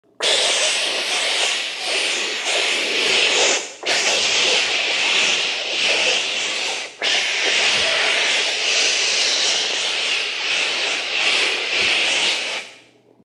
Fire Extinguisher Open Sound Effect Free Download
Fire Extinguisher Open